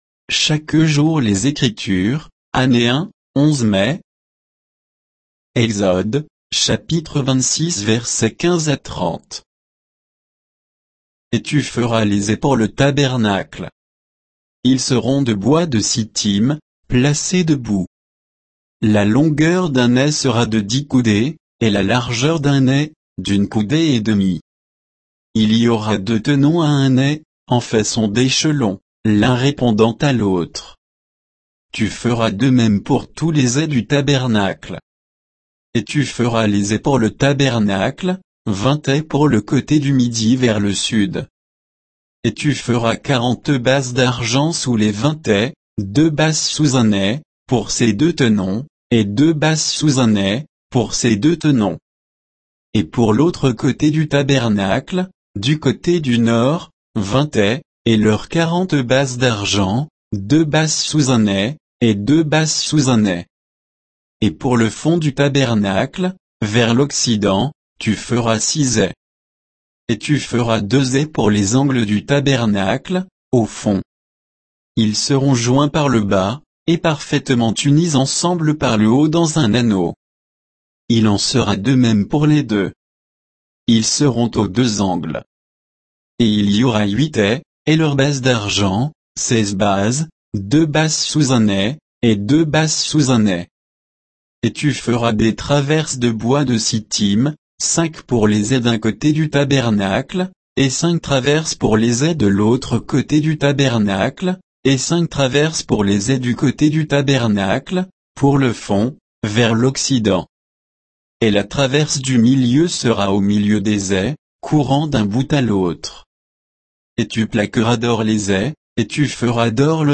Méditation quoditienne de Chaque jour les Écritures sur Exode 26, 15 à 30